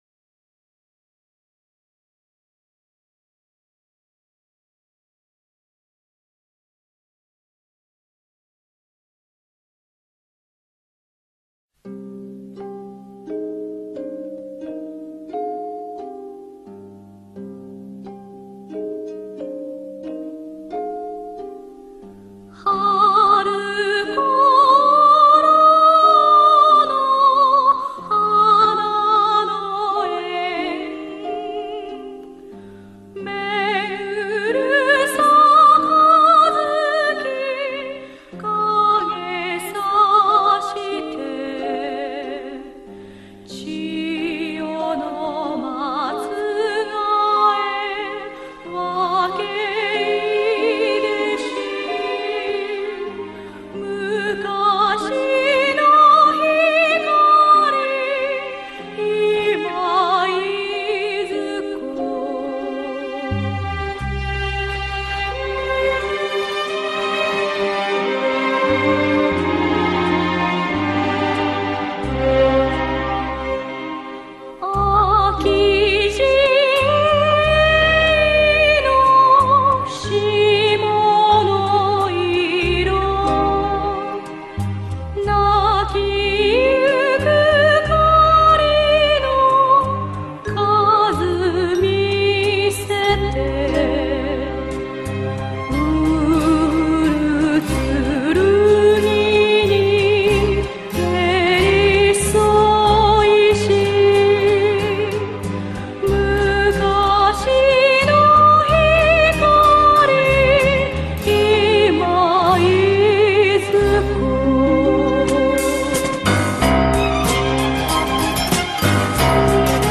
Japanese Songs